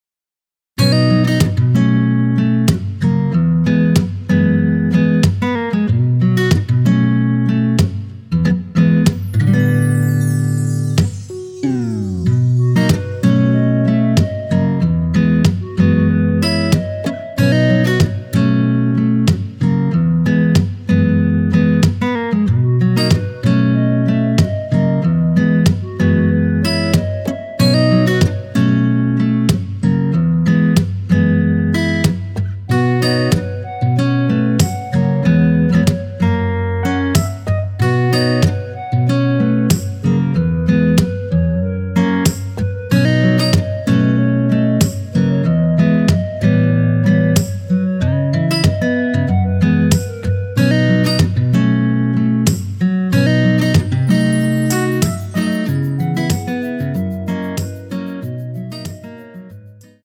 멜로디 MR 입니다.
원키에서(-2)내린 멜로디 포함된 MR입니다.
앞부분30초, 뒷부분30초씩 편집해서 올려 드리고 있습니다.